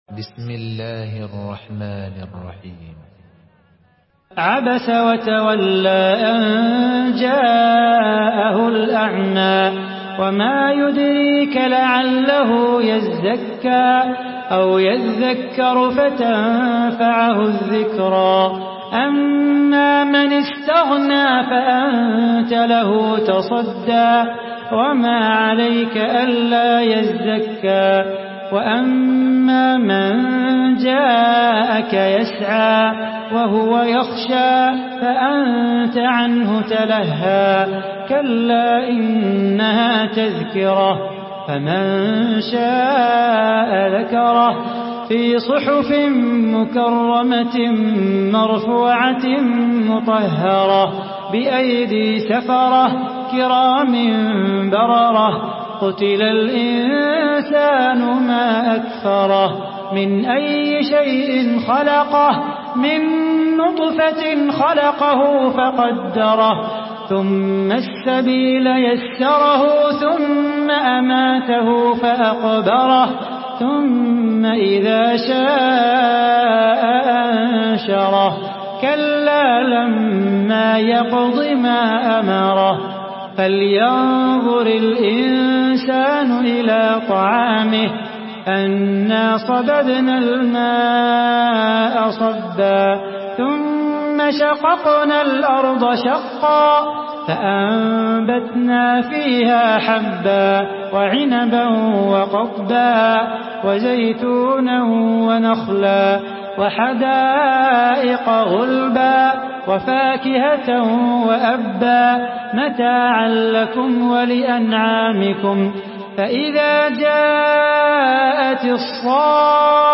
Surah Abasa MP3 in the Voice of Salah Bukhatir in Hafs Narration
Murattal